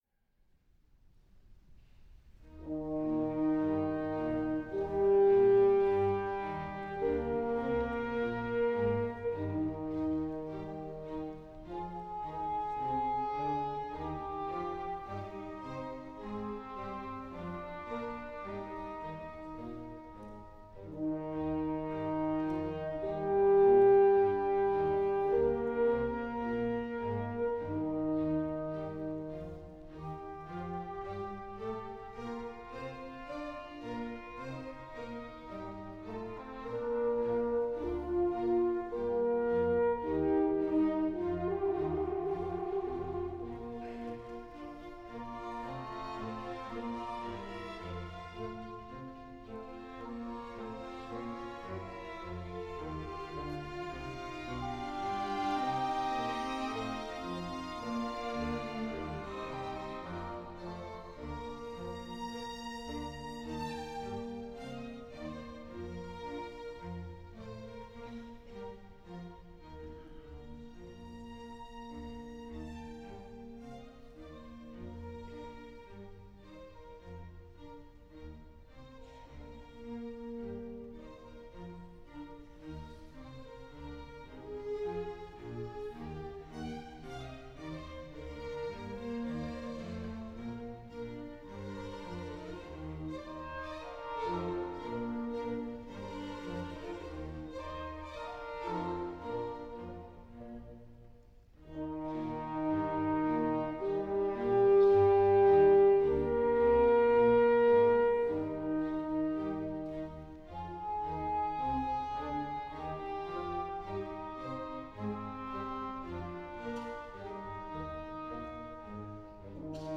Joseph Haydn: Sinfonía nº 22 en mi bemol mayor, HOB.I:22, "El Filósofo"